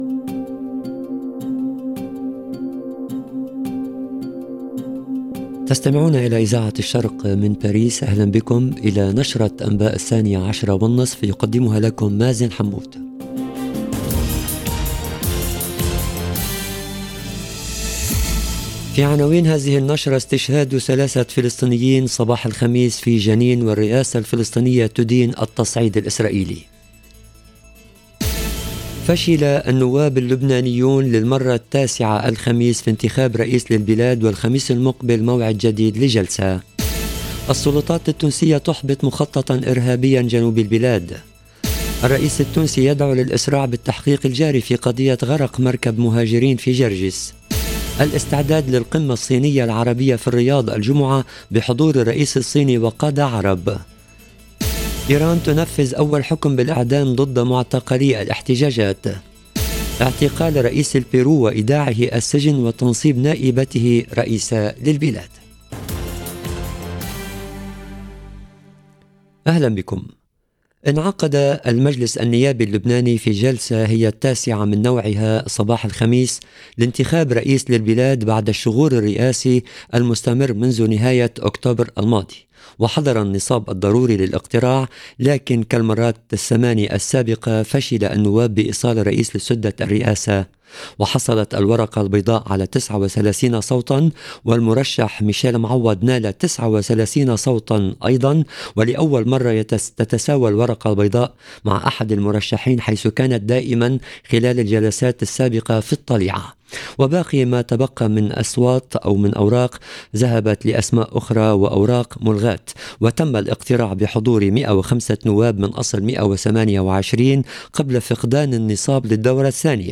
LE JOURNAL DE MIDI 30 EN LANGUE ARABE DU 8/12/22